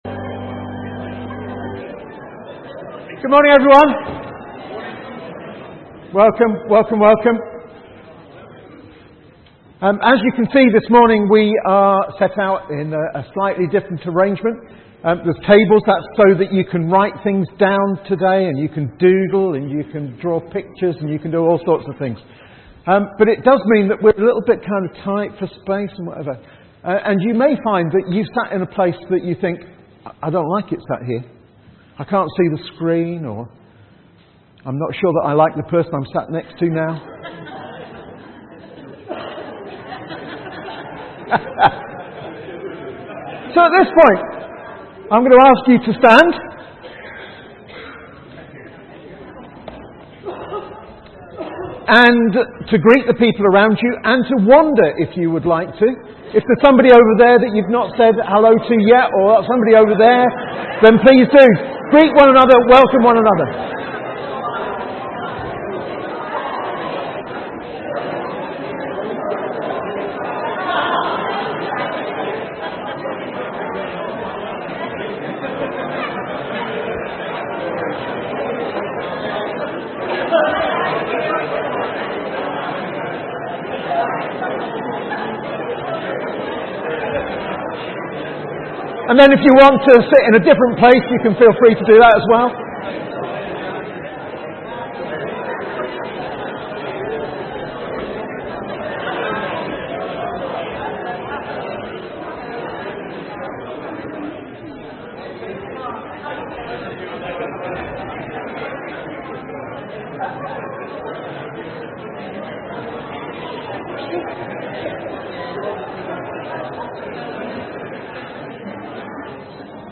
A message from the series
From Service: "10.00am Service"